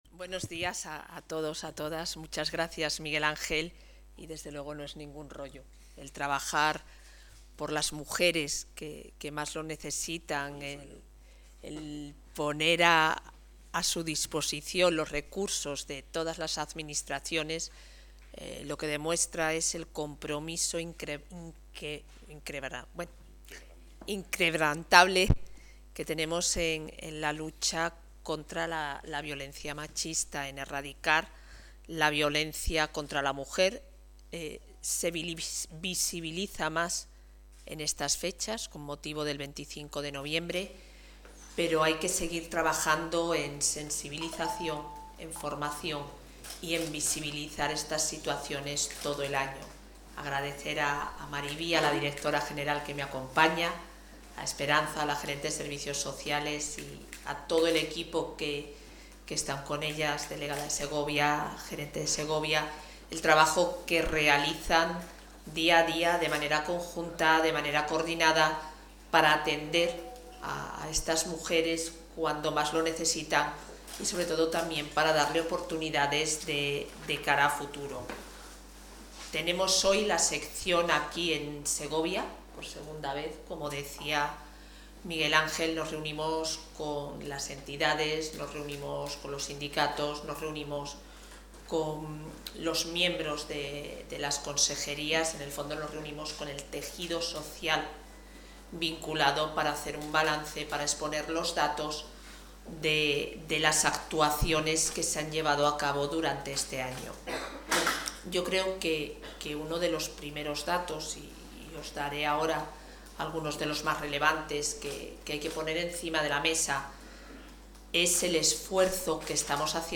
Intervención de la vicepresidenta de la Junta.